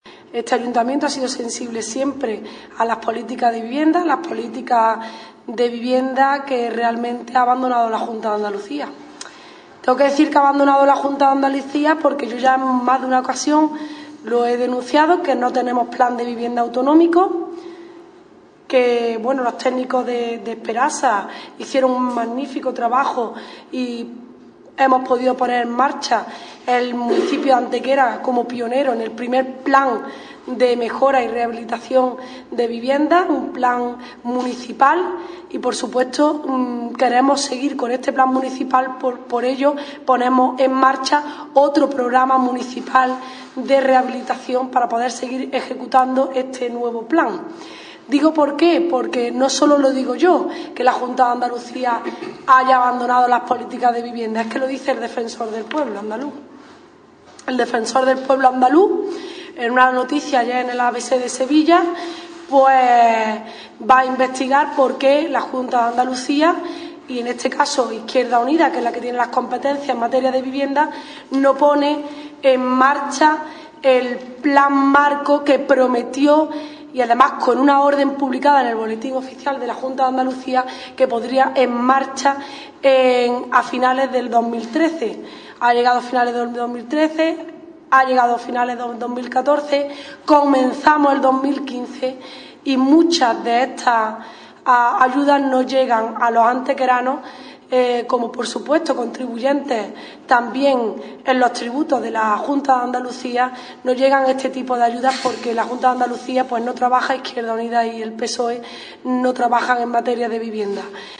Generar Pdf miércoles 14 de enero de 2015 La concejal María Dolores Gómez confirma que habrá un nuevo Plan Municipal de Rehabilitación de Viviendas 2014-2015 dotado con 200.000 euros Generar Pdf La concejal delegada de Vivienda y Participación Ciudadana del Ayuntamiento de Antequera, María Dolores Gómez, ha confirmado en rueda de prensa que el Equipo de Gobierno llevará al próximo Pleno Ordinario del presente mes de enero la propuesta para la aprobación del Plan Municipal de Rehabilitación de Viviendas 2014-2015.